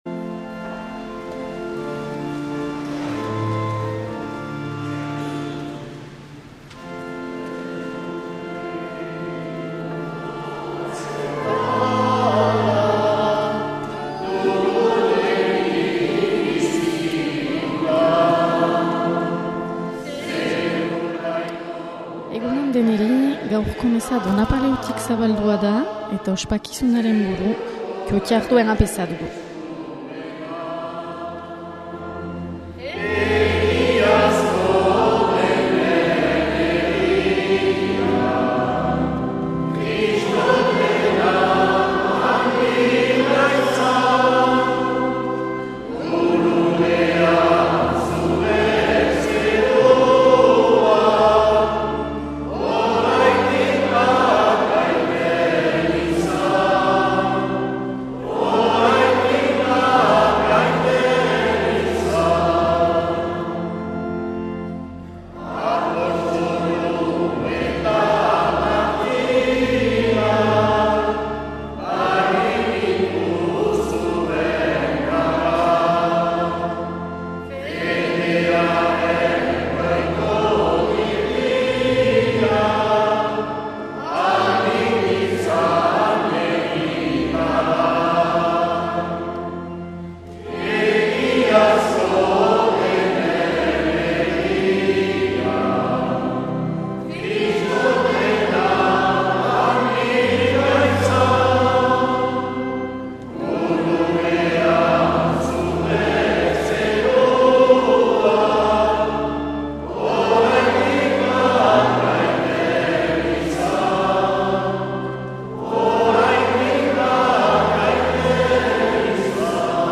2025-11-01 Omia Saindu Donapaleu
Igandetako Mezak Euskal irratietan